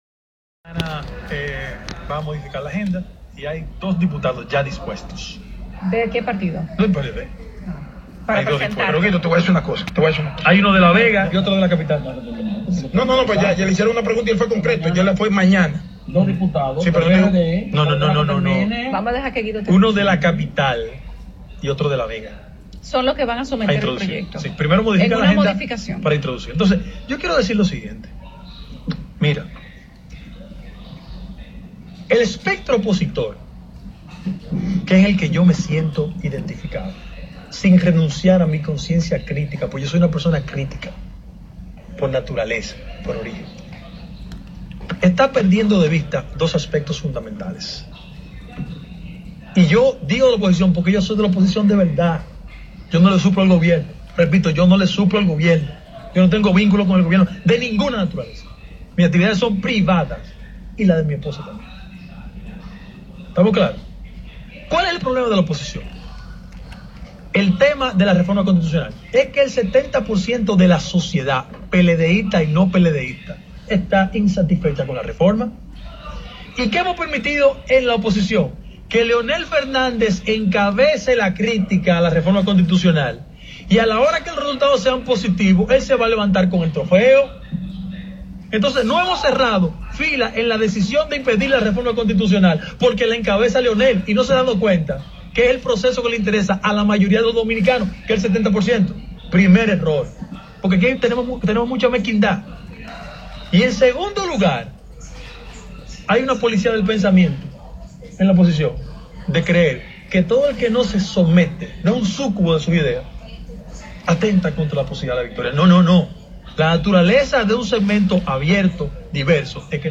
Entrevistado en el programa El Gobierno de la Tarde, de la Z101, Gómez Mazara criticó además la actitud de los opositores al Gobierno, de quienes recriminó que le han dejado el tema de la modificación constitucional al sector que dentro del partido de Gobierno lidera el ex presidente Leonel Fernández, para que éste último se lleve la corona.